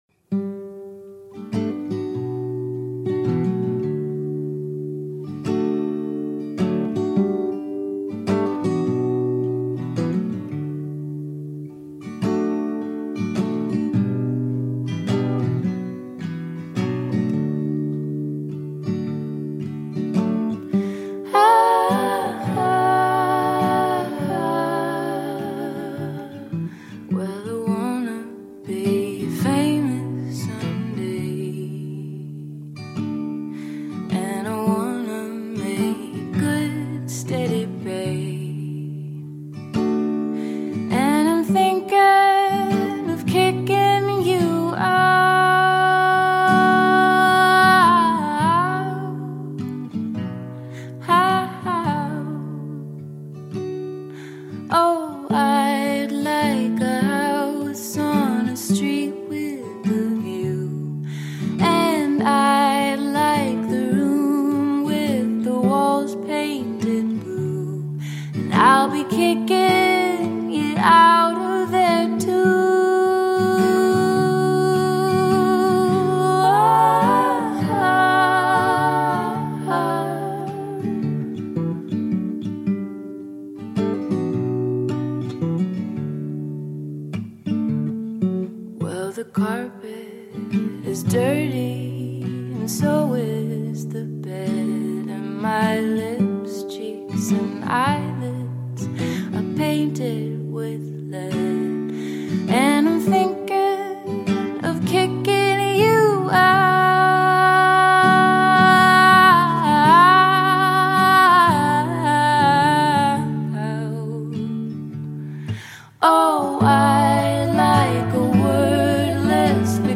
indie-folk